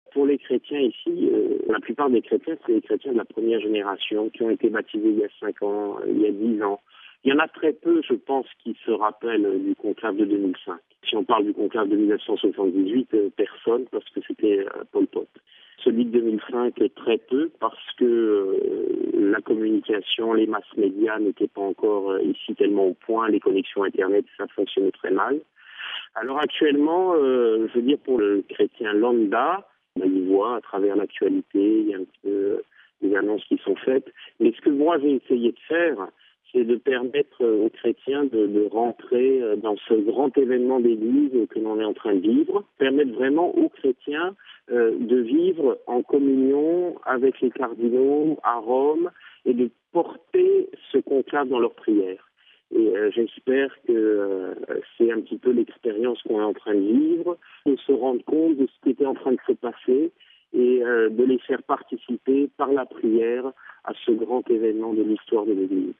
Le Père Schmitthaeusler l'exprime au micro